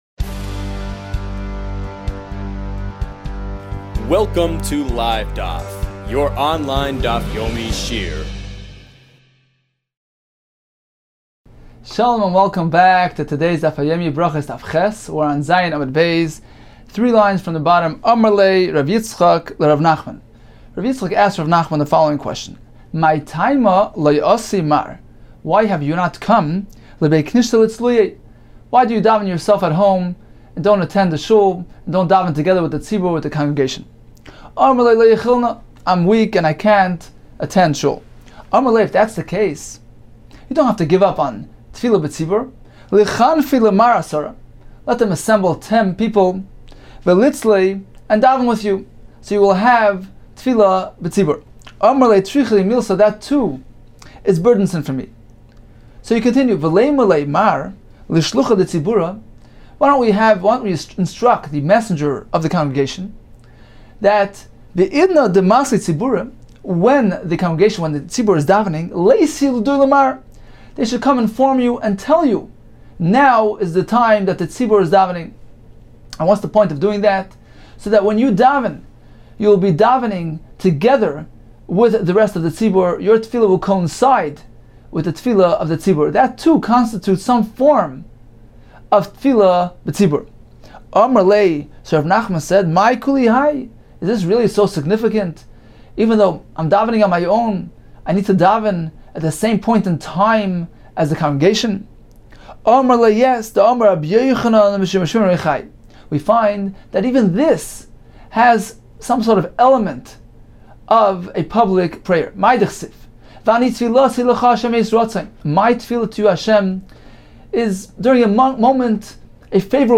This is a post of a Daf Yomi shiur on Brachos 8, along the Daf Yomi study program.